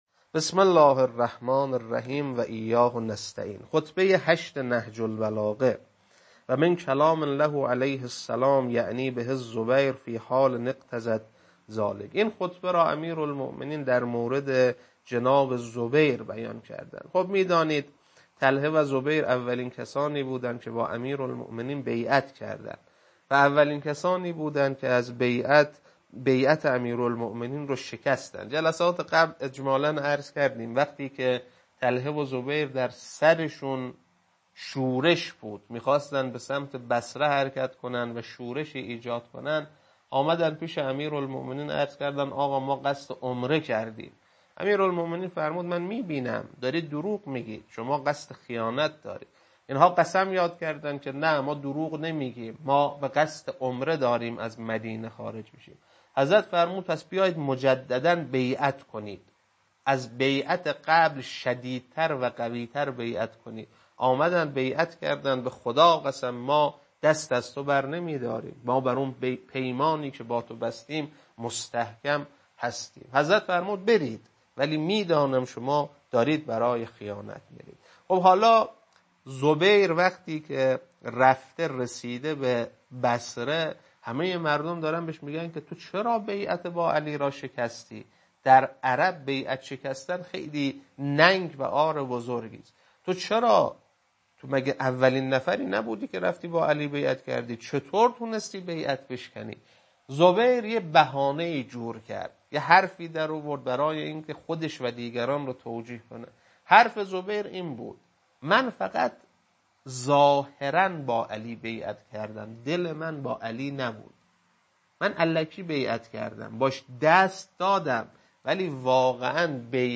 خطبه 8.mp3